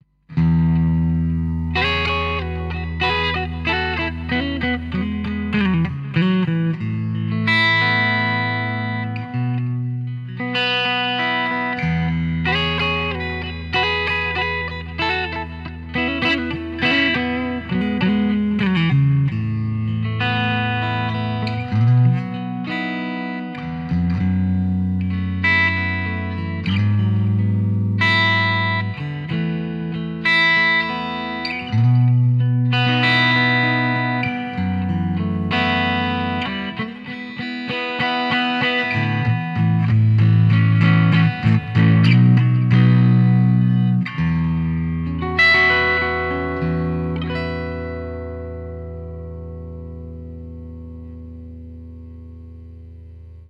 Wer die Originalverstärker kennt, hört, dass die Klänge sehr ordentlich gemodelt werden und die typische Marshall-Charakteristik eindeutig erkennbar ist.
• Gibson Les Paul
• Shure SM57
marshall_code_100_test__jtm_45.mp3